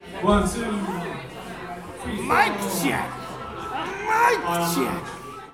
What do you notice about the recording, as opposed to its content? Bootleg media